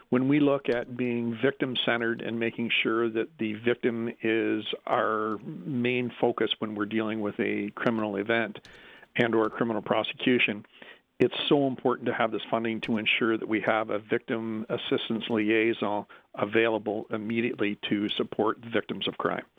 Belleville Police Chief Mike Callaghan tells Quinte News the local police service will expand its current referral system to better support survivors and will increase education and awareness in the police service, to help ensure frontline officers are aware of all available resources to help those victimized.